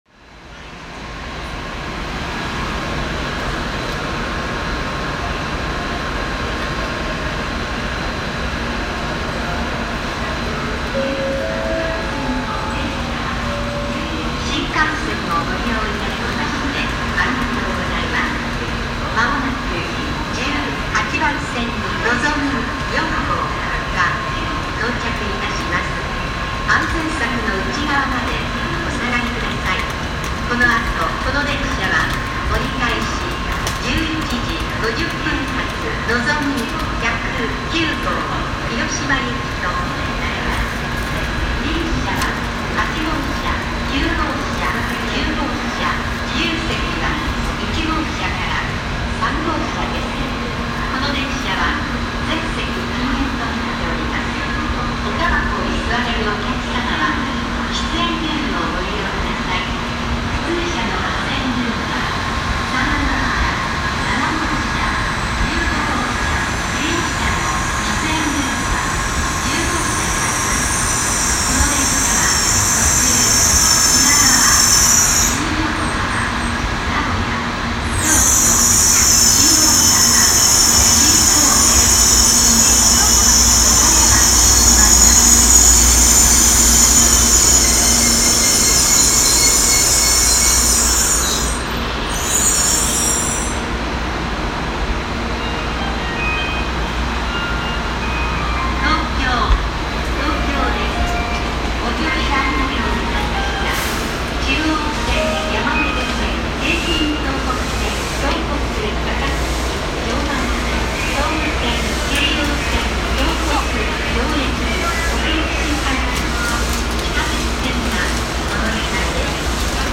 The Shinkansen bullet train arrives into Tokyo station - we can hear the throb of the stationary train's engines, plus announcements and the distinctive, specific melody that's used to signify the Shinkansen's arrival.